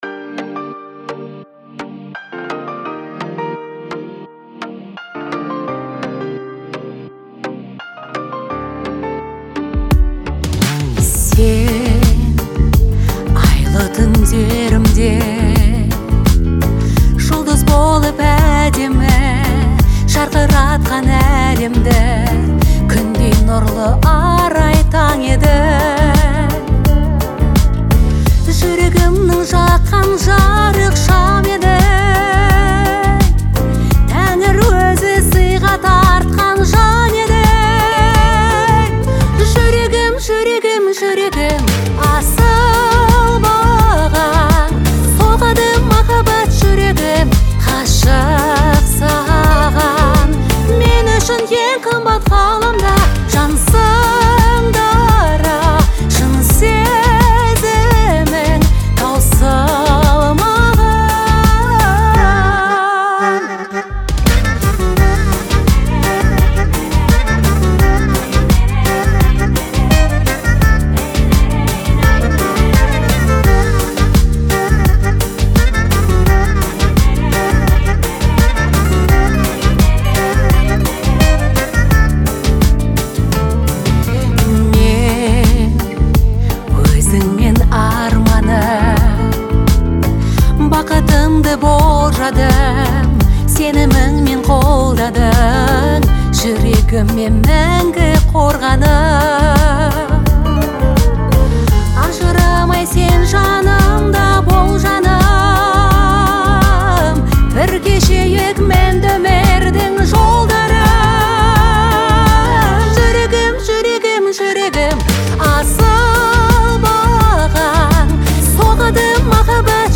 казахской певицы
который сочетает в себе элементы поп и этнической музыки.